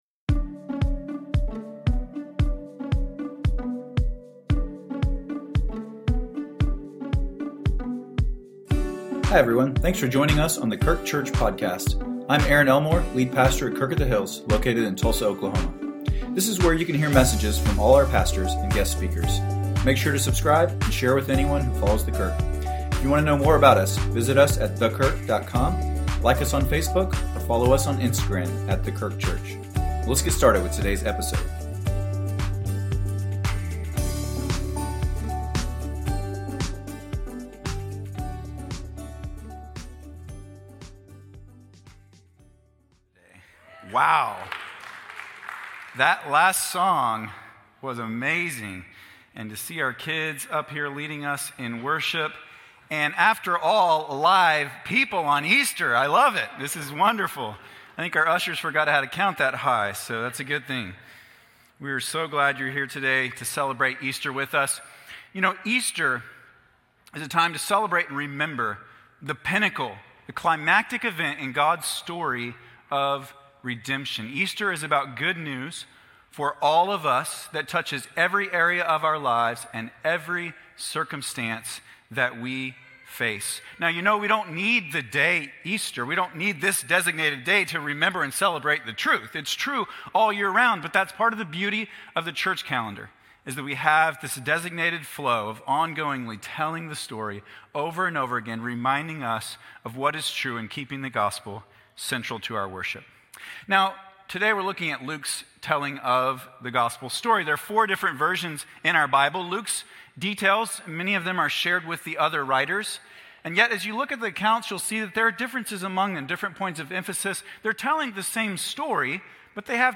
A message from the series "Holy Week."